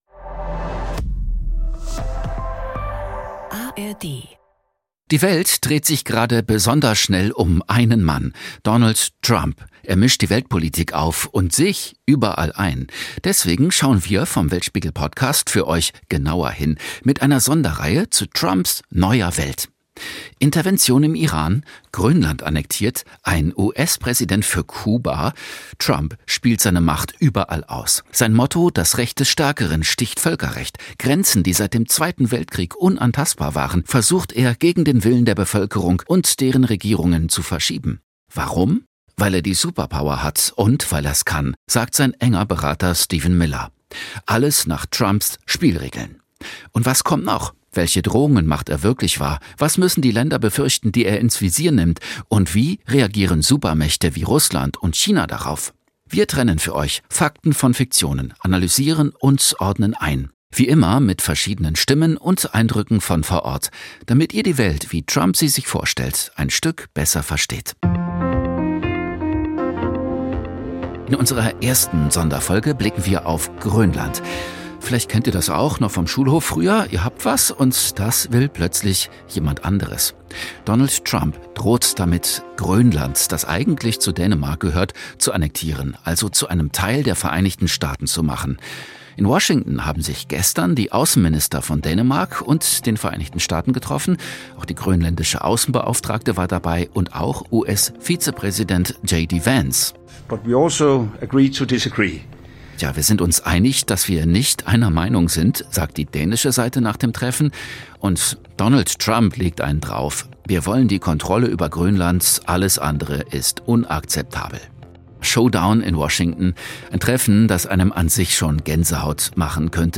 Wir trennen für euch Fakten von Fiktion, analysieren und ordnen ein – wie immer mit verschiedenen Stimmen und Eindrücken von vor Ort.